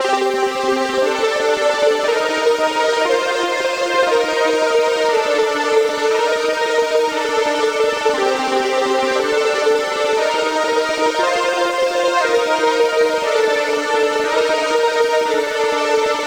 SC_Essentials_SFX_2_118_bpm_OE9_NvW.wav